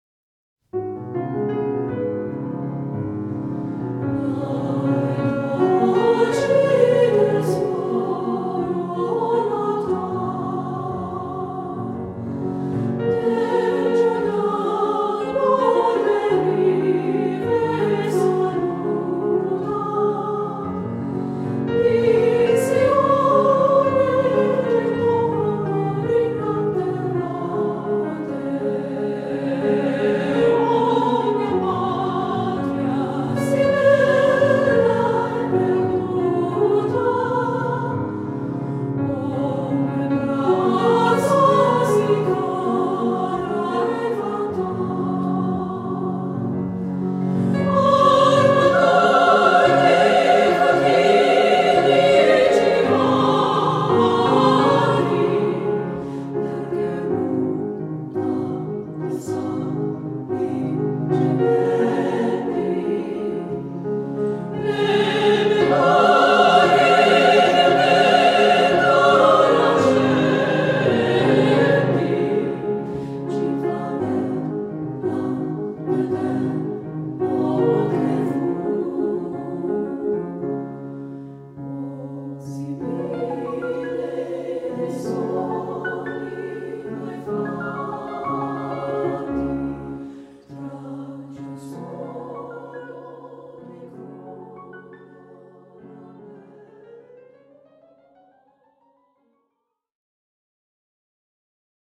Voicing: SSAA